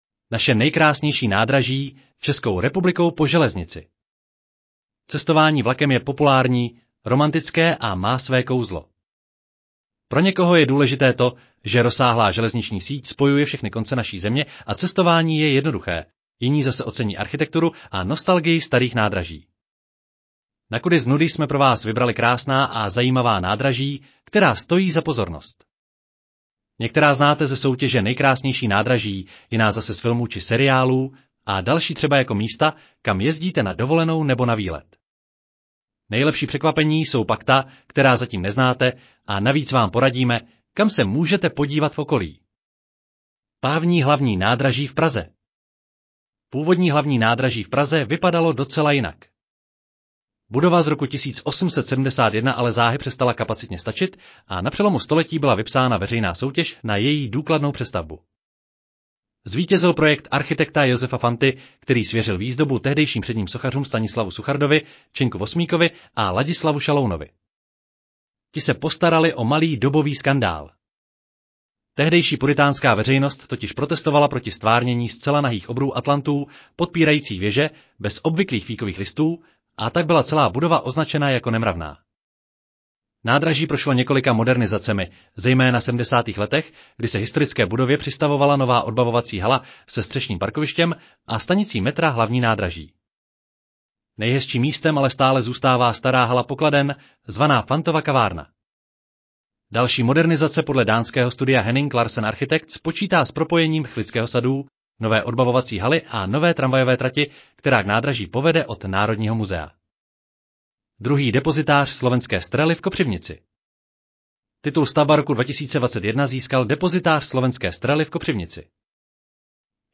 Audio verze článku Naše nejkrásnější nádraží: Českou republikou po železnici